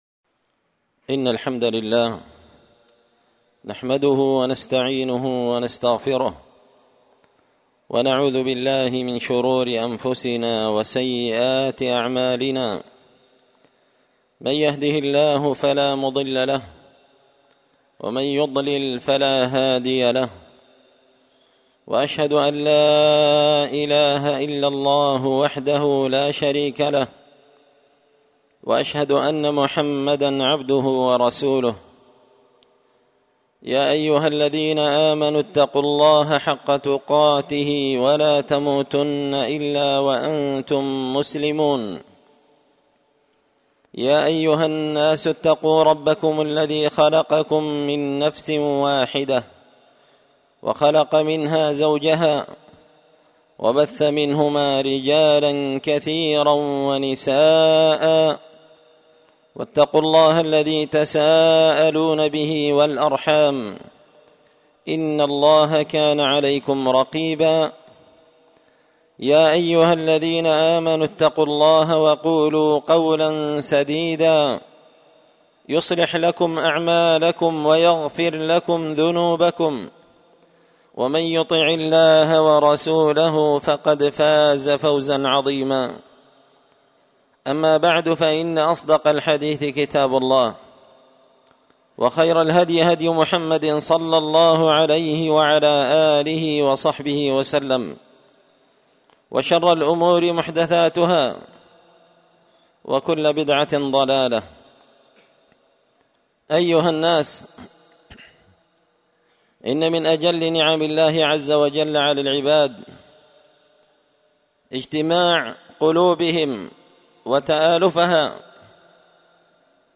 خطبة جمعة بعنوان – تذكير المؤمنين بفضل الصلح بين المتخاصمين
دار الحديث بمسجد الفرقان ـ قشن ـ المهرة ـ اليمن